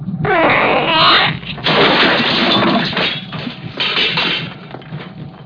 "DON'T TOUCH IT... DON'T TOUCH IT!!" shouts Ash.
The alien lets out a shrill call.....